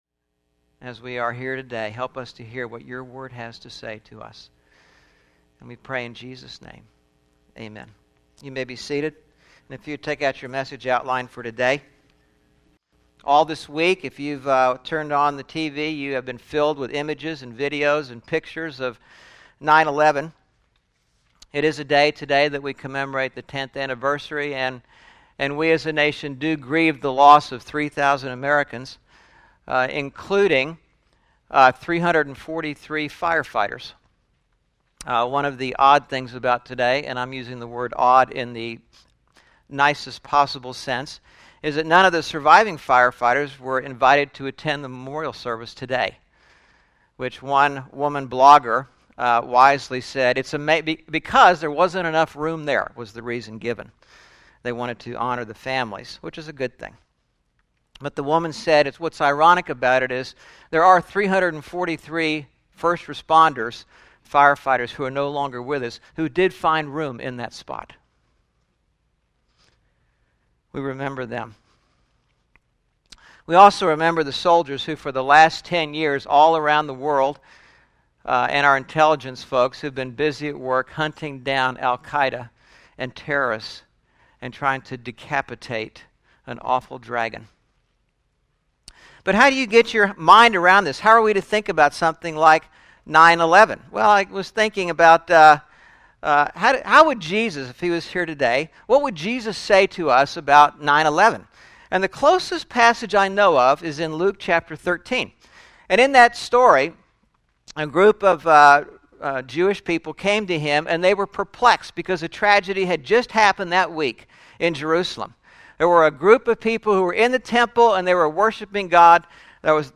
9/11/2011 Sermon (The Book of 2 Kings) – Churches in Irvine, CA – Pacific Church of Irvine